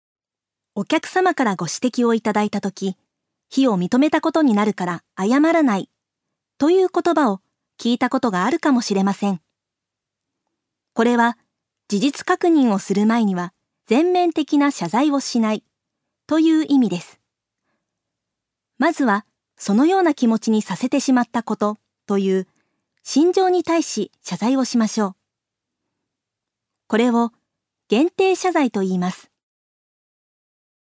女性タレント
音声サンプル
ナレーション４